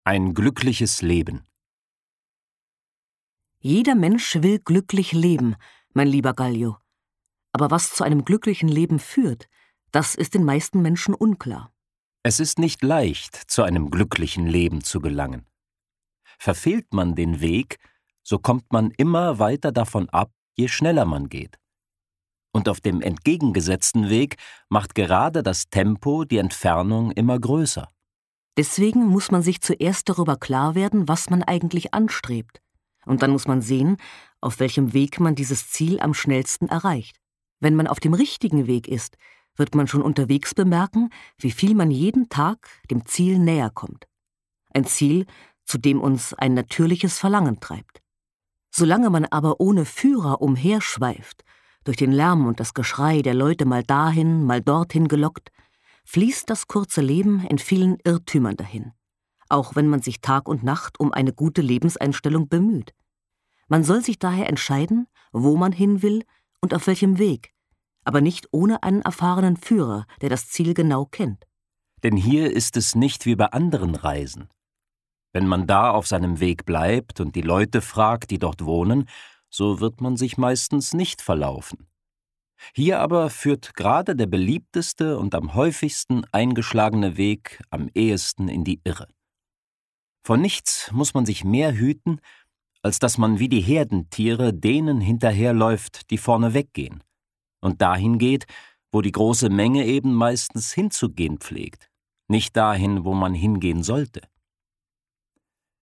horprobe-gluckliches-leben.mp3